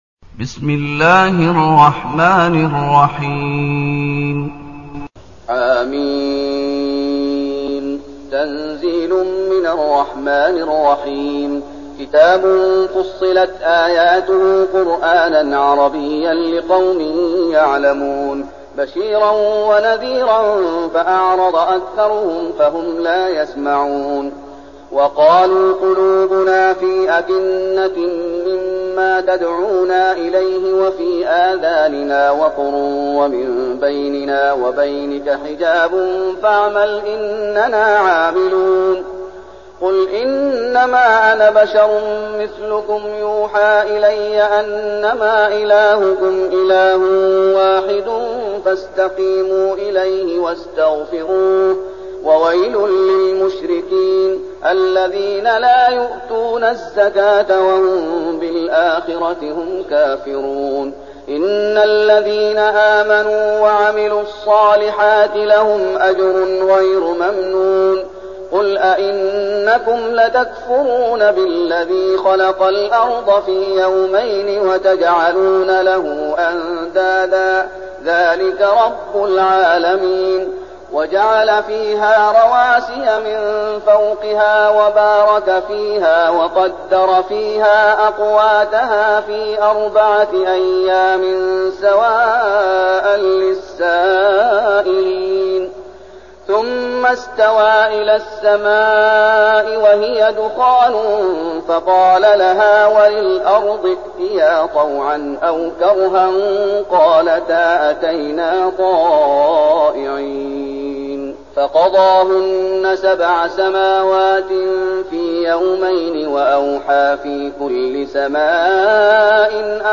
تلاوة سورة فصلت
تاريخ النشر ١ محرم ١٤١٠ المكان: المسجد النبوي الشيخ: فضيلة الشيخ محمد أيوب فضيلة الشيخ محمد أيوب سورة فصلت